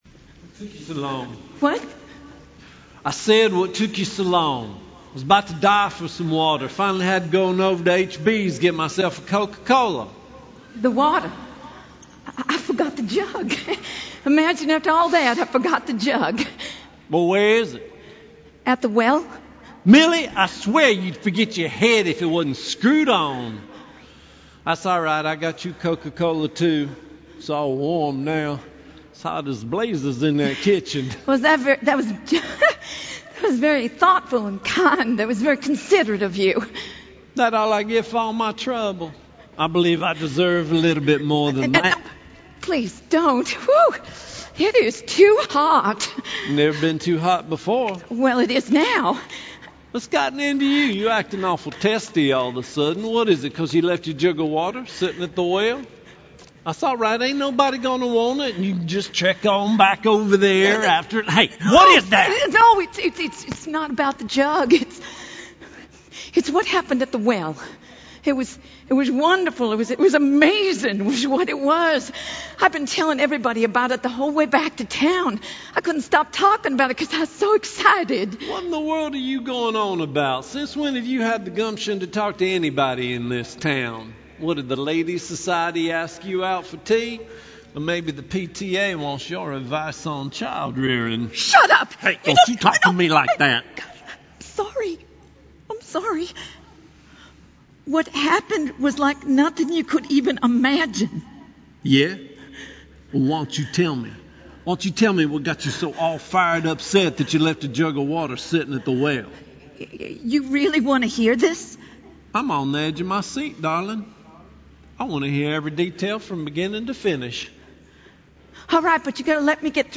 Session 2 Drama: Woman at the Well